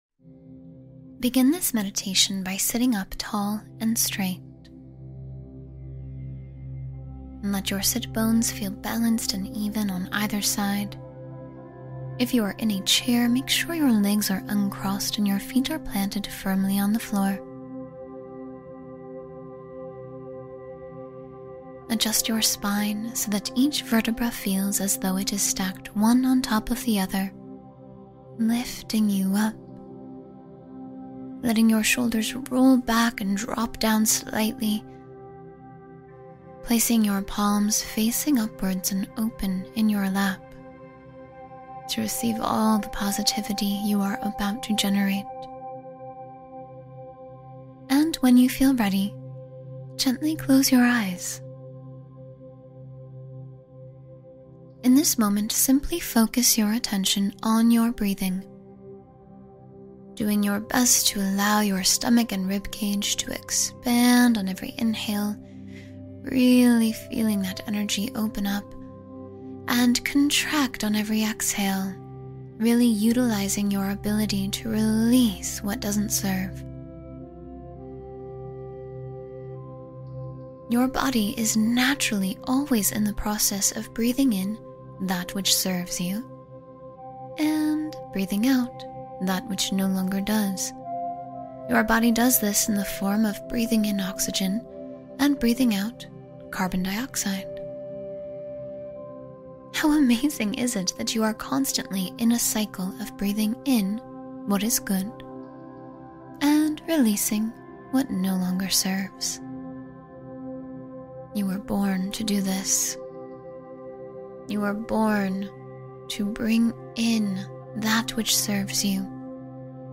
Fill Yourself with Positive Energy and Peace — Guided Morning Practice